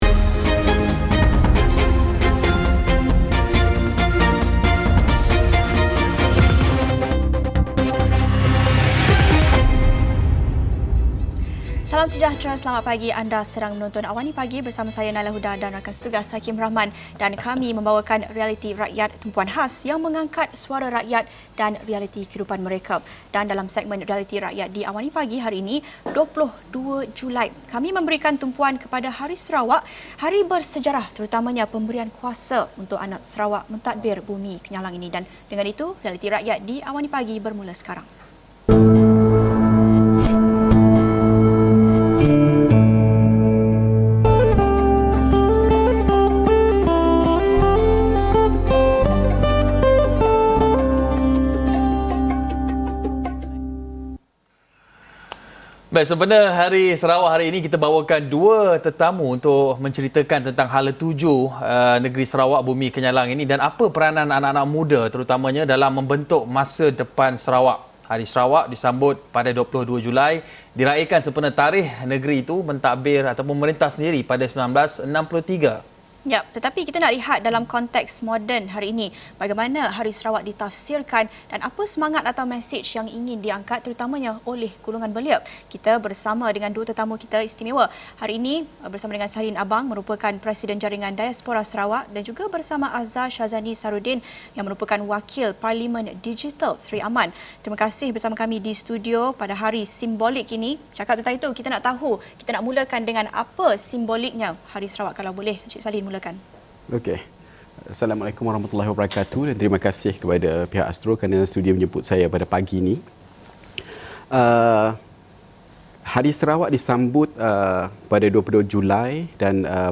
Sempena Hari Sarawak hari ini, kita bawakan dua tetamu untuk menceritakan tentang hala tuju negeri di bawah Bayu ini dan apa peranan anak muda dalam membentuk masa depan Sarawak.